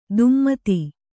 ♪ dummati